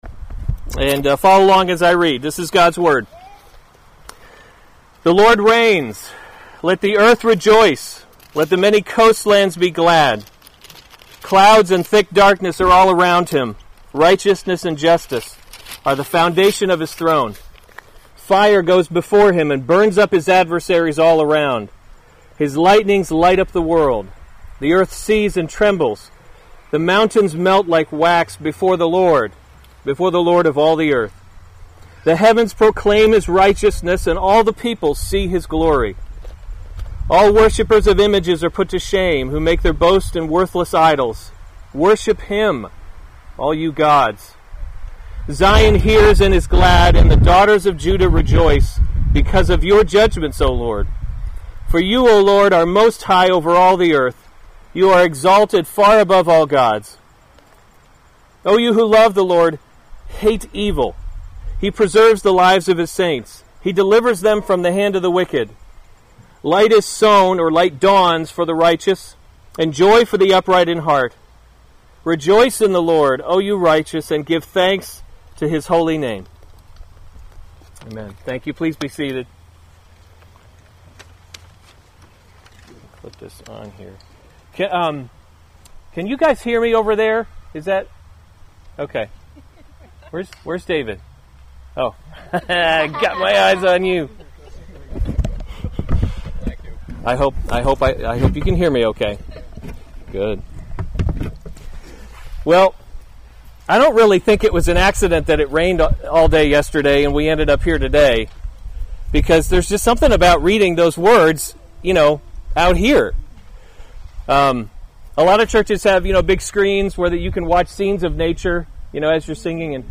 August 18, 2018 Psalms – Summer Series series Weekly Sunday Service Save/Download this sermon Psalm 97 Other sermons from Psalm The Lord Reigns 97:1 The Lord reigns, let the earth rejoice; […]